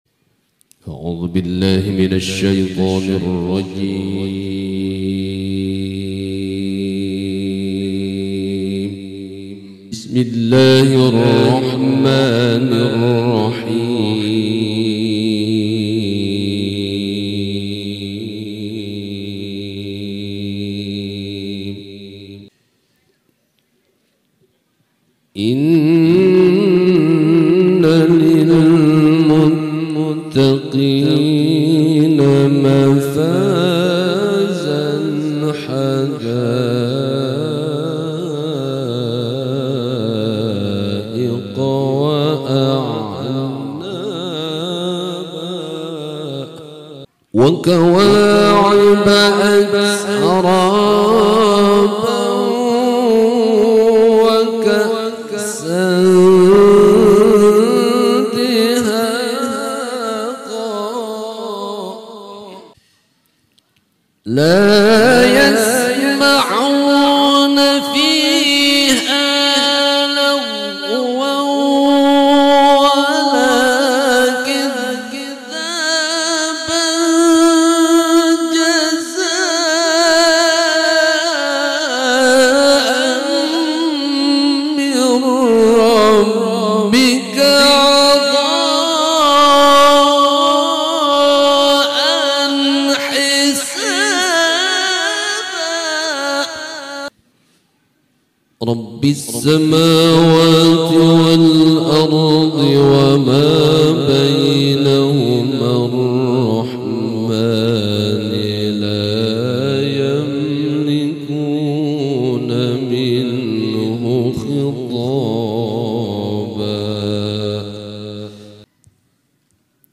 HAFLAH TILAWATIL QURAN DI ACARA Sound Effects Free Download